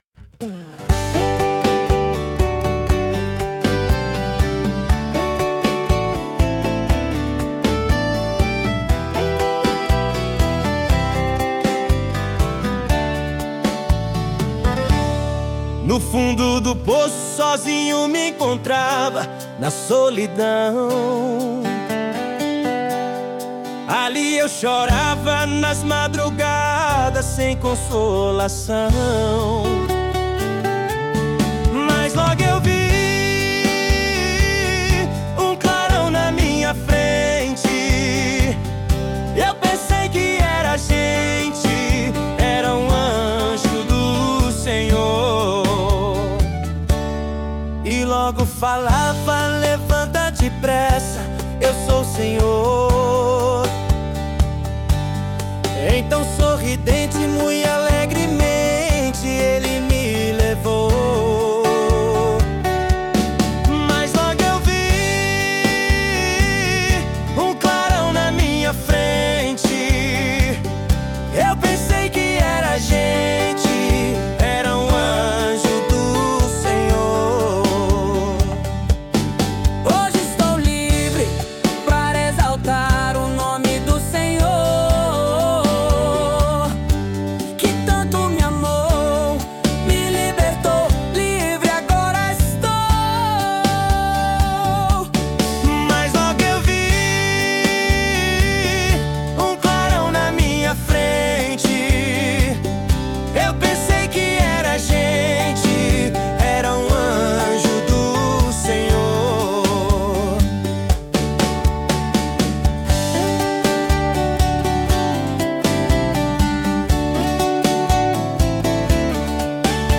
[Vocal Masculino] [Instrumental Intro]